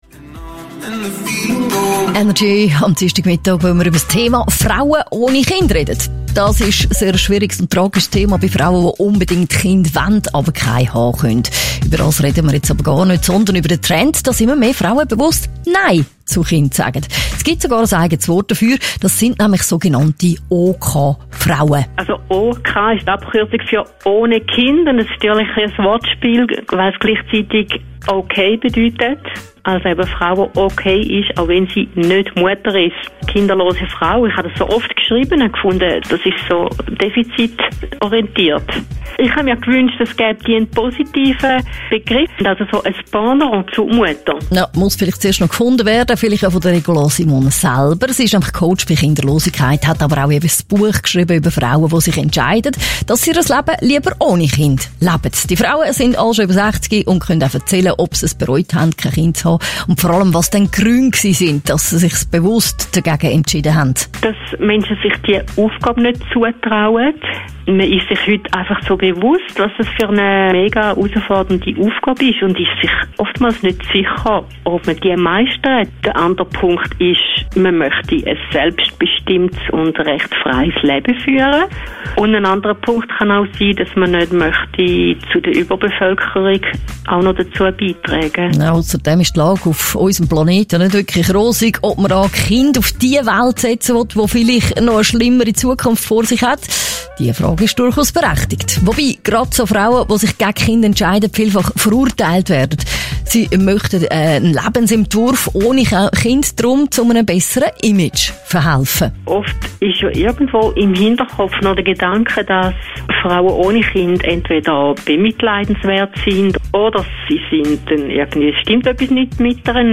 Darum habe ich mir erlaubt, die beiden Ausschnitte, welche von dem Interview ausgetrahlt wurden, auf meine Website hochzuladen, so dass ihr sie euch anhören könnt.
Vorteil des Ostschweizerdialektes ist, dass Deutsche und Österreicherinnen, die mithören möchten, evtl. eher etwas verstehen.